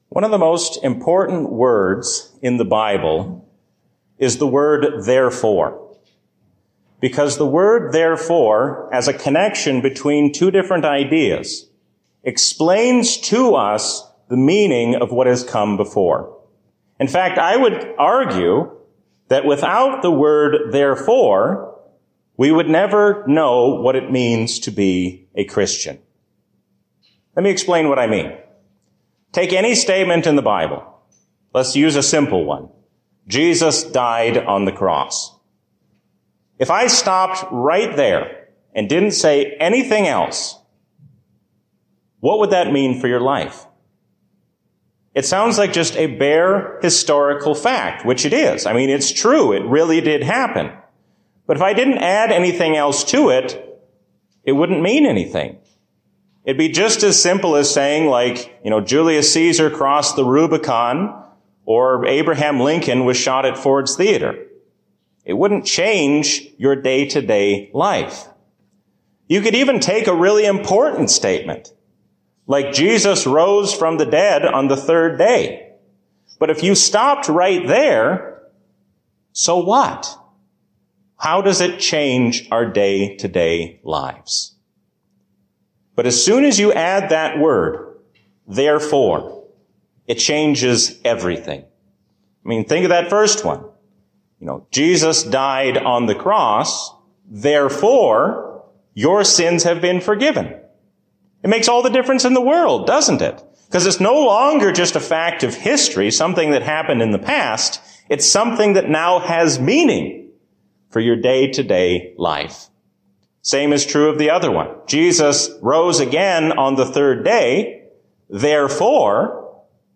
A sermon from the season "Easter 2022." Let us be Christians not only in our words, but also in what we do.